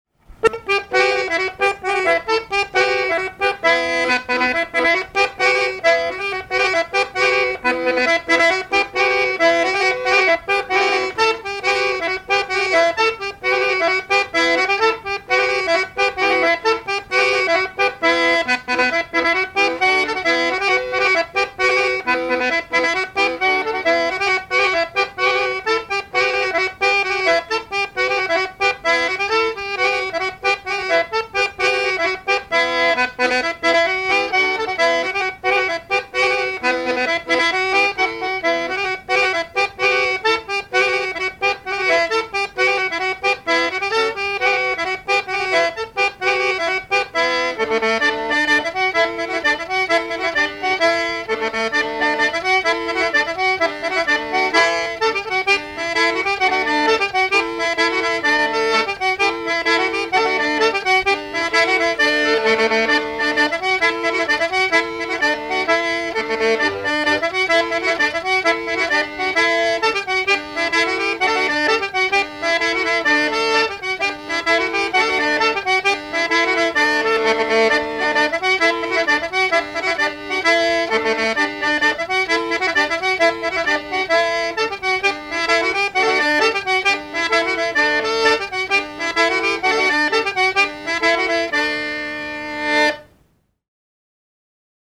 danse : branle
Répertoire d'airs à danser
Pièce musicale inédite